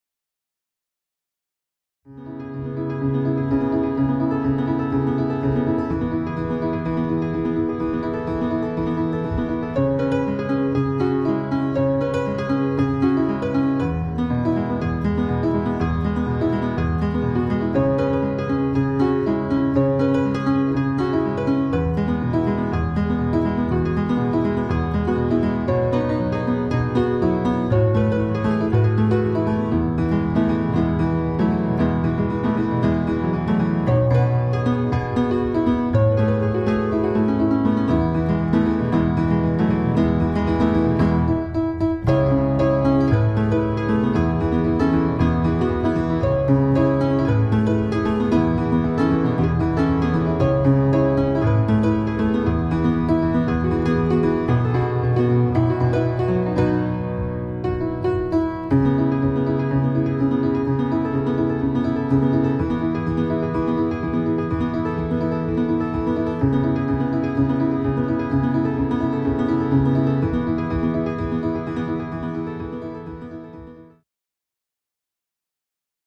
• Indie/Rock
Piano